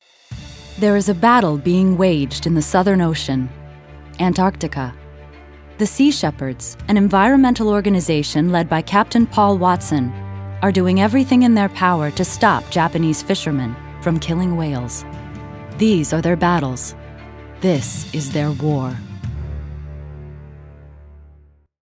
standard us
documentary